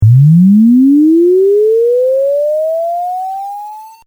Here’s a swept sine wave, first simply truncated to seven bits:
7-bit sine sweep, no dither
Note the aliased frequencies in the non-dithered sweep, and how the tail sounds like a square wave as it fades out.
7bit_sweep_no_dither.mp3